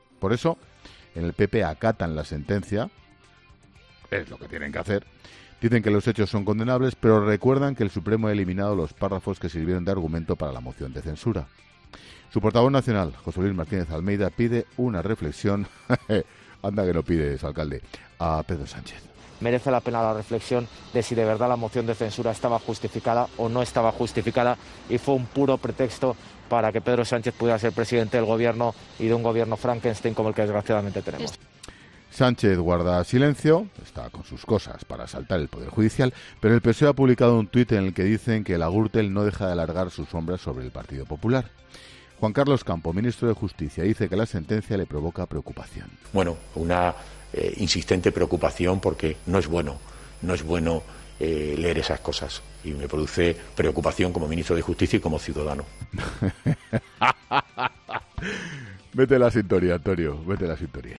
El presentador de 'La Linterna' no ha podido contener las carcajadas al escuchar algunas declaraciones al respecto
No sin antes incluir, en medio de este comentario, un paréntesis entre risas: “¡Anda que no pides, alcalde!”.
Ante esto, Expósito no ha podido hacer otra cosa que reírse a carcajadas en directo para cerrar su monólogo.